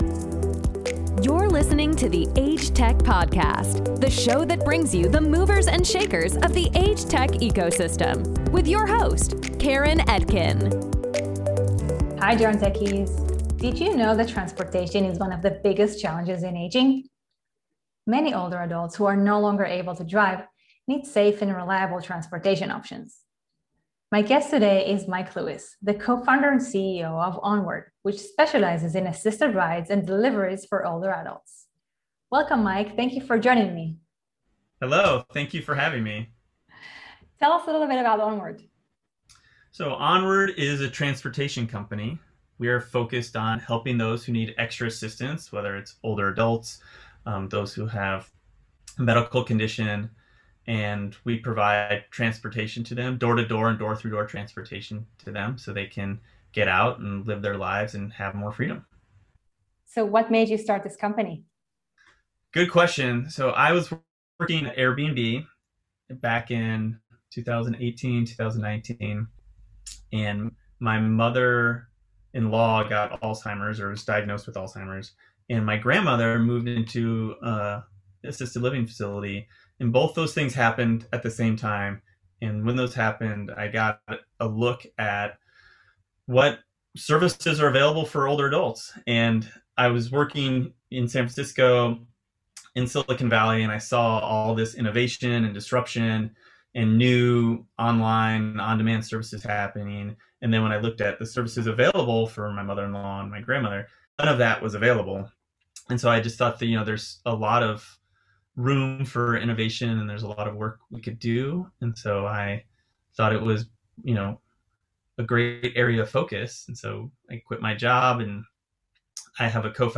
onward-interview-final-cut.mp3